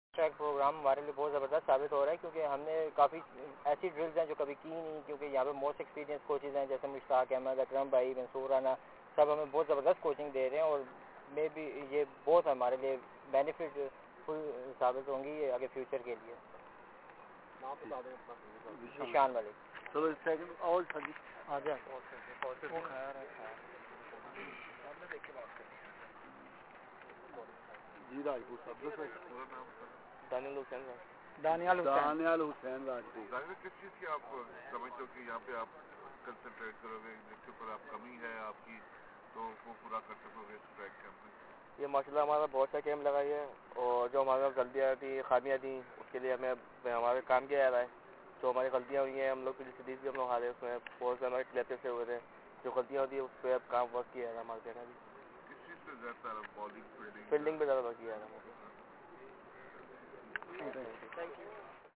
media talk at Gaddafi Stadium Lahore